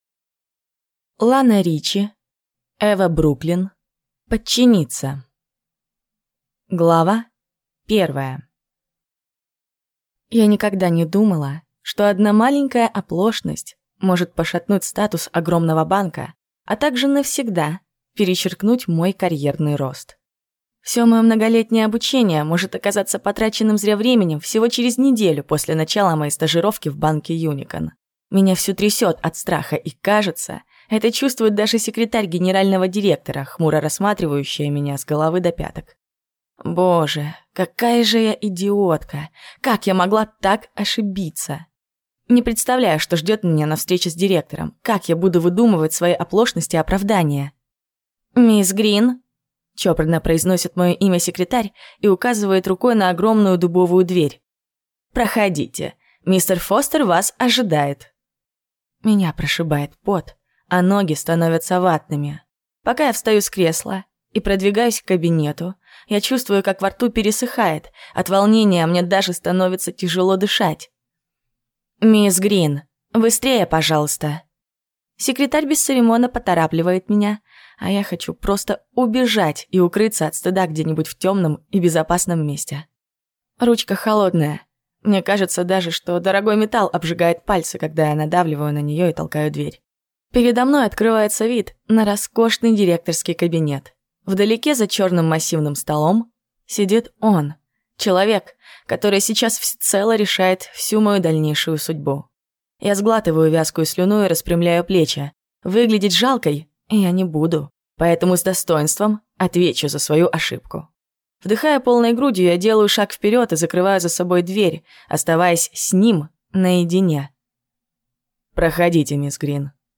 Аудиокнига Подчиниться | Библиотека аудиокниг